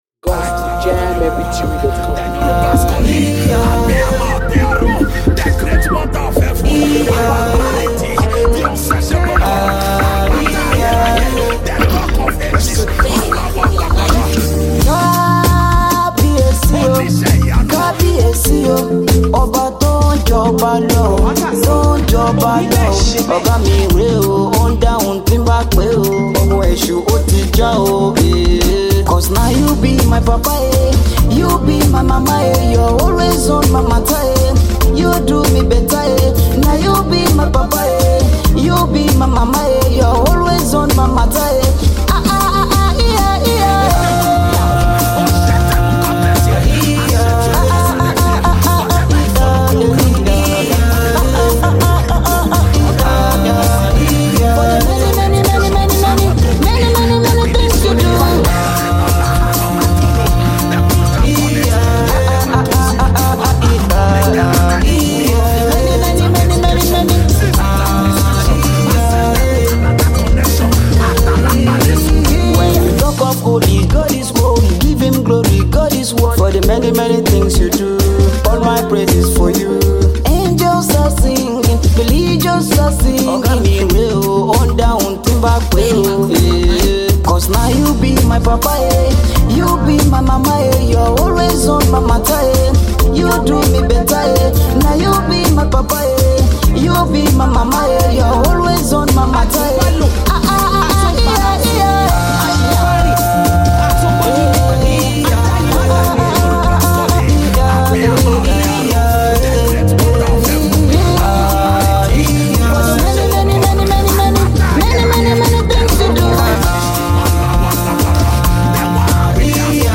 🤩 As The Gifted Nigerian Gospel Music Minister